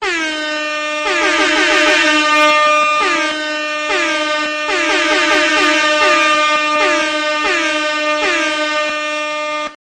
airhorn_alarm.mp3